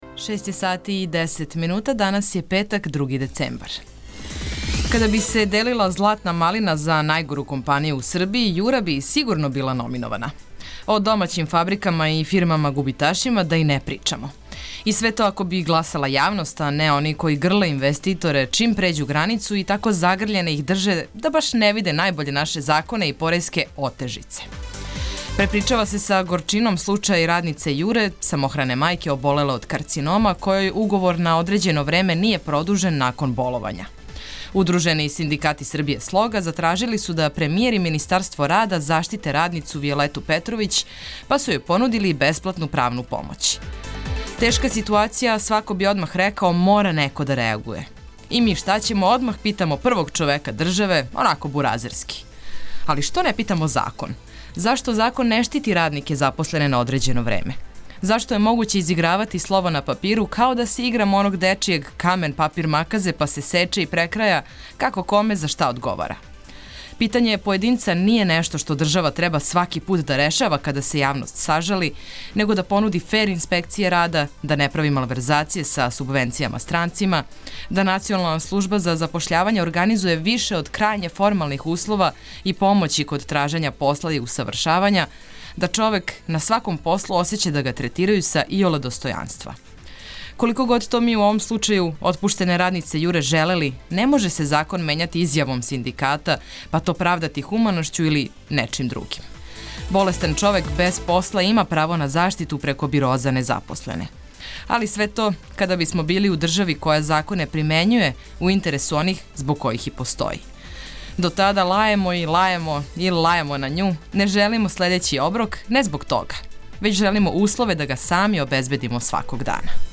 Наше јутро са вама започећемо ауторским текстом водитеља - Цртицом, који позива на ваше коментаре и сагледавање онога што је актуелно ових дана. За ово јутро одабрали смо причу о радници корејске фирме "Јура" из Ниша, која је отпуштена током боловања.